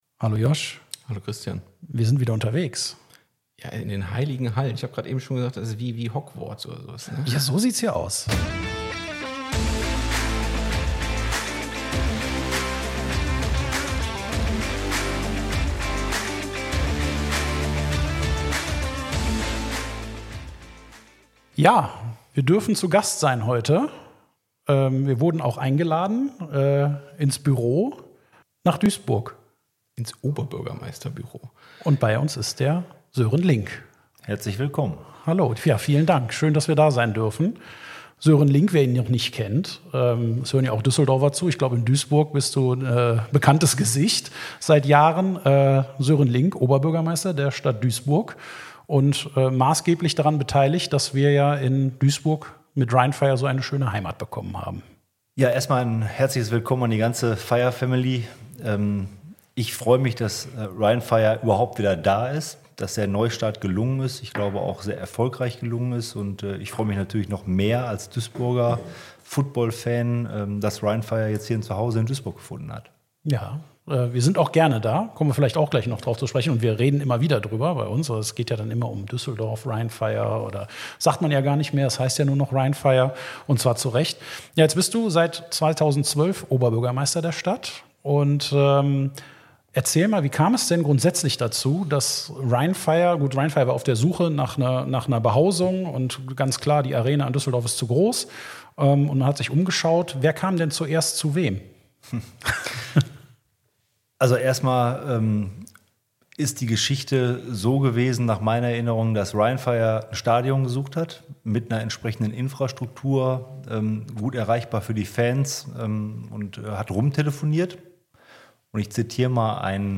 Heute sind wir zu Gast bei Sören Link, Oberbürgermeister der Stadt Duisburg.